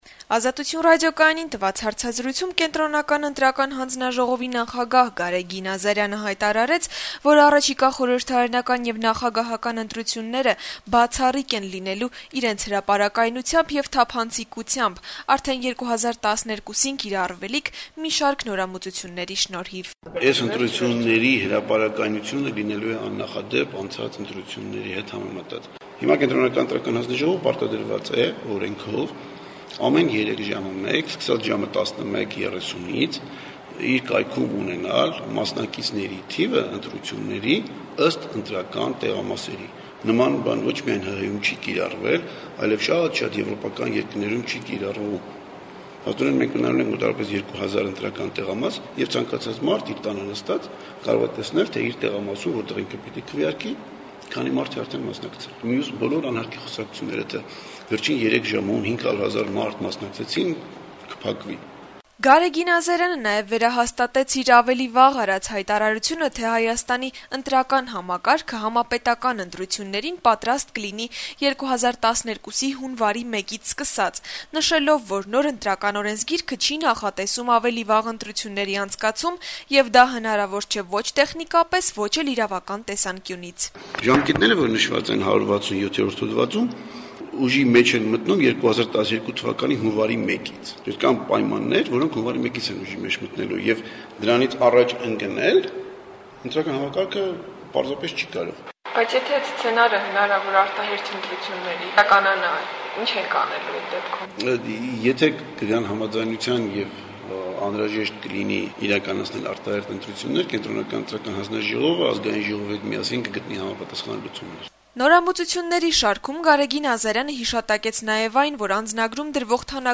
Կենտրոնական ընտրական հանձնաժողովի (ԿԸՀ) նախագահ Գարեգին Ազարյանը երեքշաբթի օրը «Ազատություն» ռադիոկայանին տված հարցազրույցում հայտարարեց, որ առաջիկա խորհրդարանական եւ նախագահական ընտրությունները բացառիկ են լինելու իրենց հրապարակայնությամբ եւ թափանցիկությամբ` արդեն 2012 թվականին կիրառվելիք մի շարք նորամուծությունների շնորհիվ: